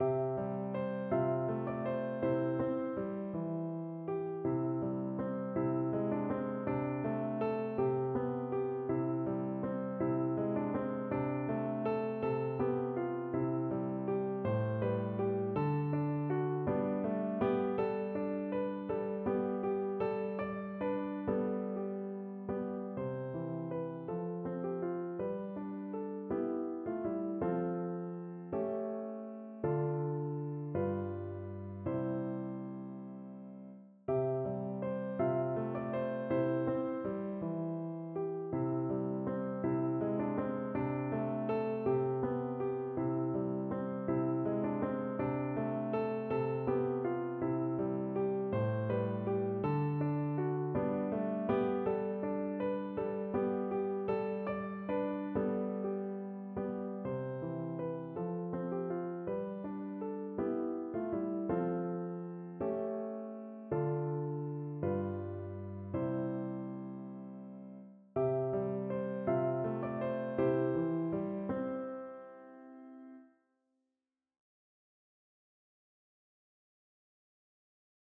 No parts available for this pieces as it is for solo piano.
6/8 (View more 6/8 Music)
C major (Sounding Pitch) (View more C major Music for Piano )
Andantino semplice. . = 54 (View more music marked Andantino)
Piano  (View more Intermediate Piano Music)
Classical (View more Classical Piano Music)
Christmas_Song_PNO.mp3